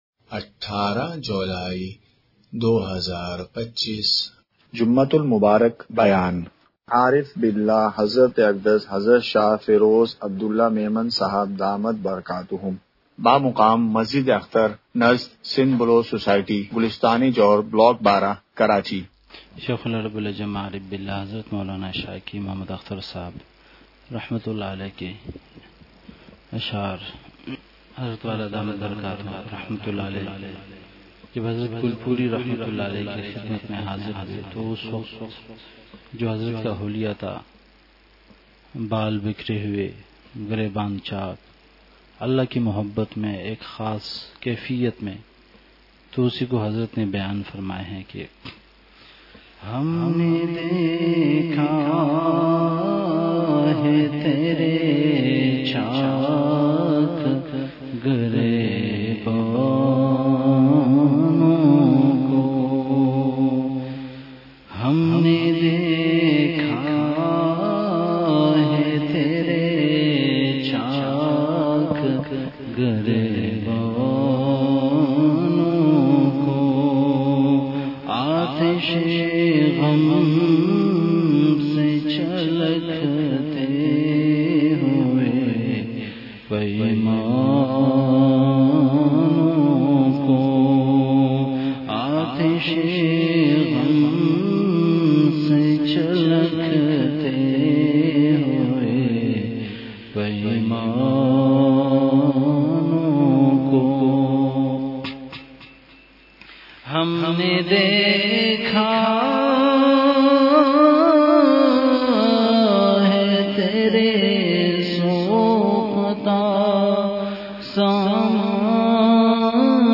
جمعہ بیان ۱۸ جولائی ۲۵ء:تہجد کے فضائل اور آسان قیام الیل کا طریقہ !
مقام:مسجد اختر نزد سندھ بلوچ سوسائٹی گلستانِ جوہر کراچی